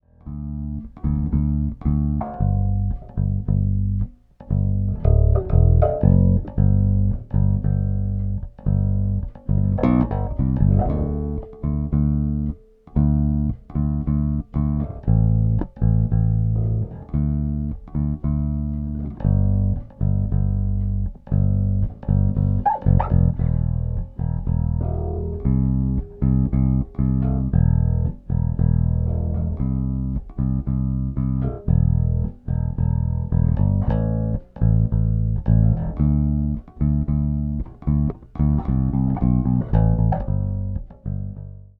Hätt´s probiert, gab aber nur Ravioli aus der Dose, also ist es beim stimmen vom Bass geblieben your_browser_is_not_able_to_play_this_audio Dafür mal die Audiodatei.... aufgenommen nach dem Input, ohne Effekte oder sonstiges Gedöns. Natürlich in Audacity hochgezogen damit man überhaupt was hört. (Am besten finde ich das Schlagzeug im Hintergrund, spar ich mir die Abnahme davon.)
Etwas doch: ab ca. 14K Hz steigt das Rauschen an.